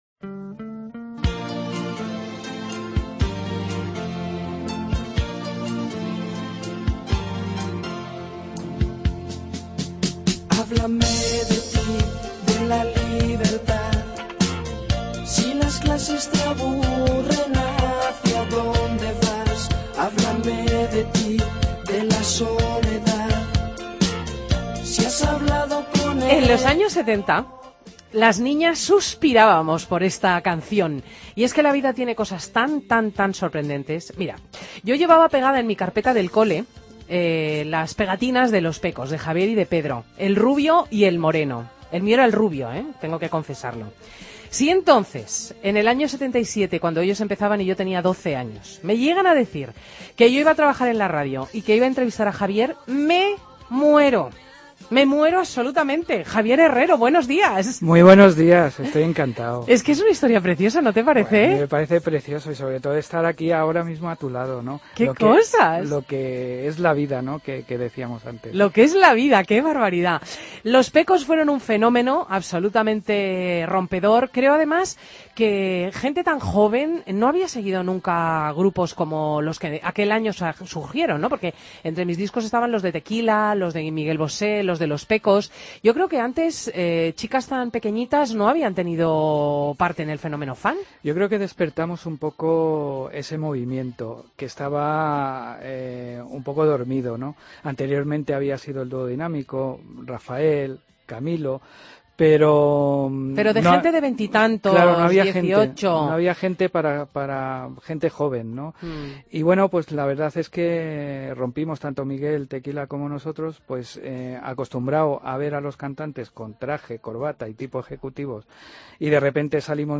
Entrevista a Javier Herrero, de Los Pecos, en Fin de Semana